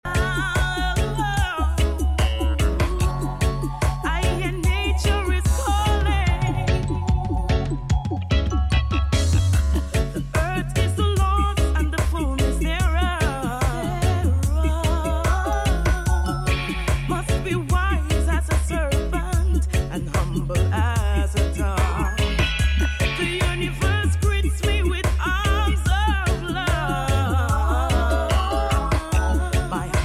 Reggae Ska Dancehall Roots